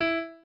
piano2_34.ogg